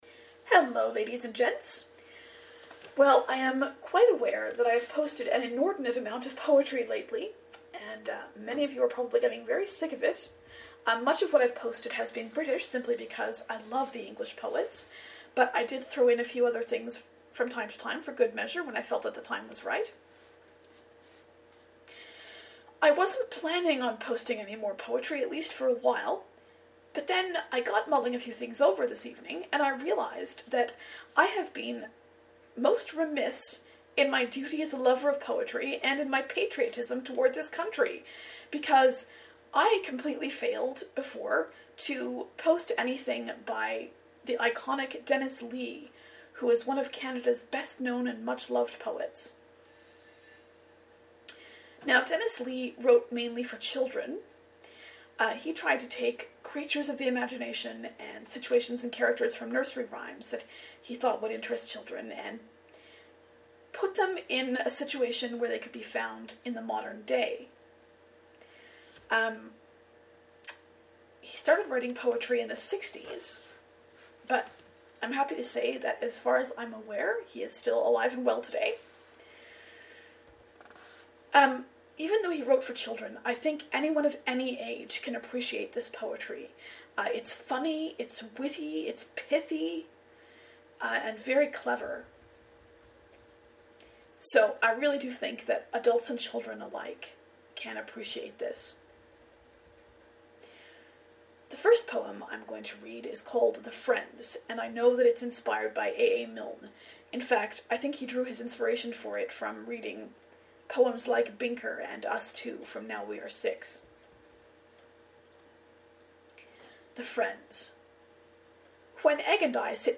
Here is a bit more poetry, but it's so fuuuuuuuuun! This is by Dennis Lee, and honestly, it's a lot of fun both to read and to listen to.